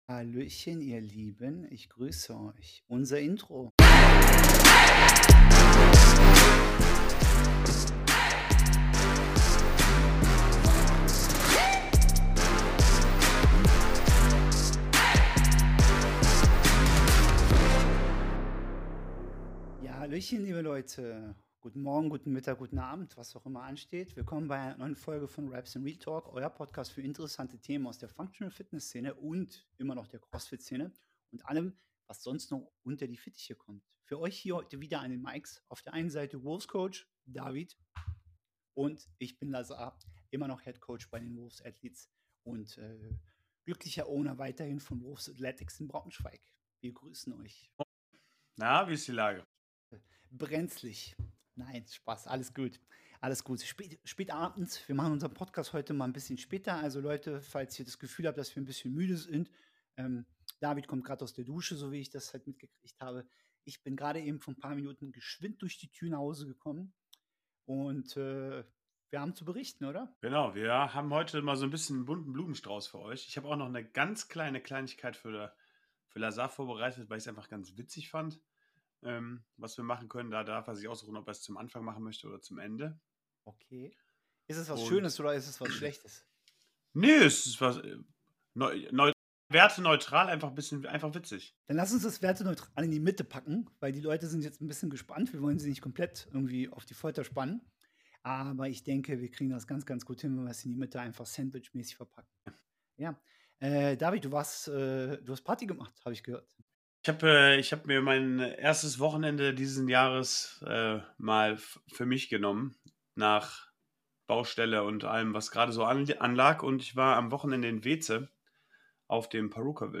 In dieser Episode diskutieren die Gastgeber die emotionalen Aspekte des Sports, insbesondere im Kontext von Athletenwechseln und der Ethik im Sport. Sie beleuchten die Herausforderungen der Professionalisierung und die Notwendigkeit einer besseren Kommunikation innerhalb des Verbands.